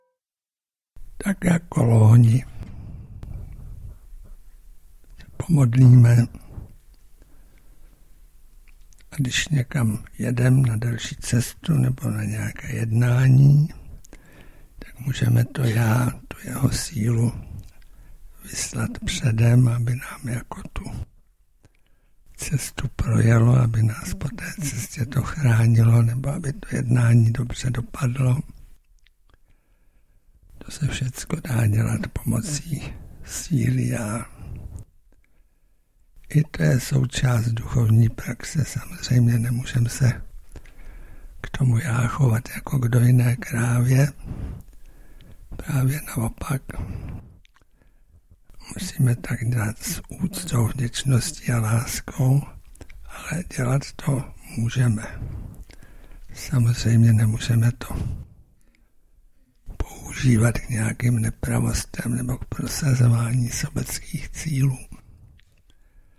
Nejedná se o studiovou nahrávku.